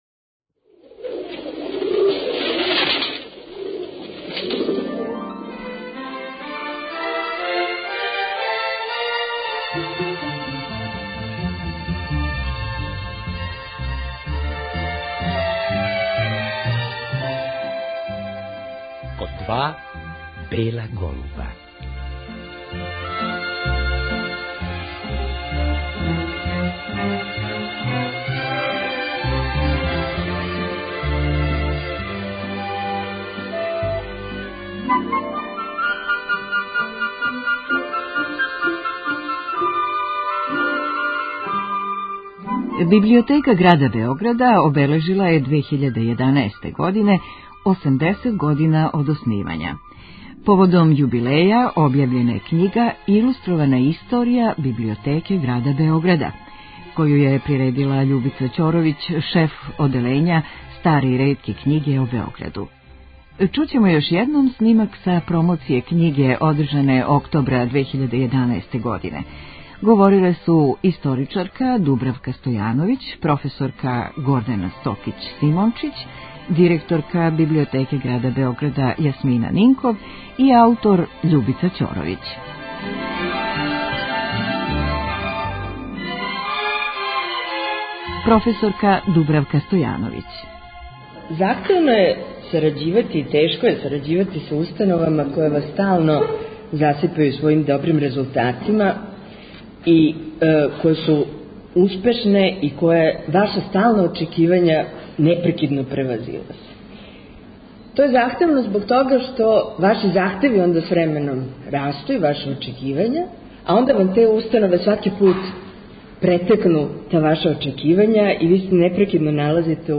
Чућемо још једном снимак са промоције књиге одржане октобра 2011. године.